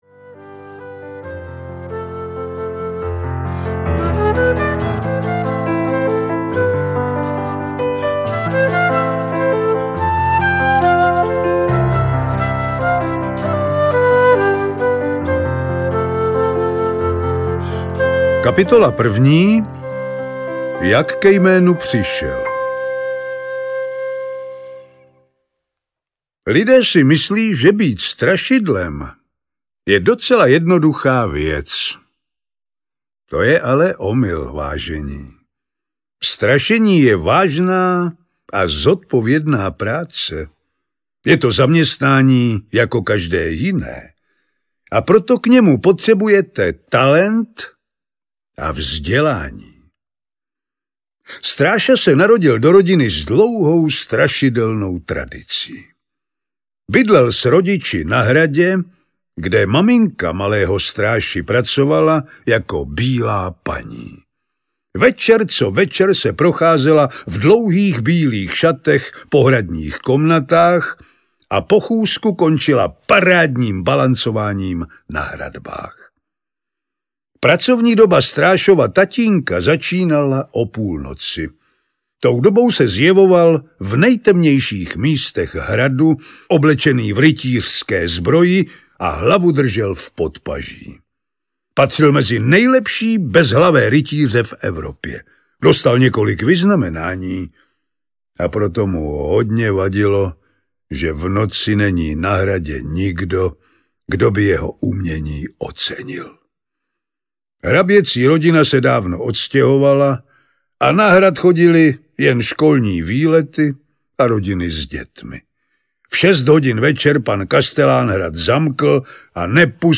Strašidýlko Stráša audiokniha
Ukázka z knihy
Jedinečná audioknižní dramatizace první knížky oblíbené spisovatelky Aleny Mornštajnové určené pro nejmenší čtenáře.
• InterpretRůzní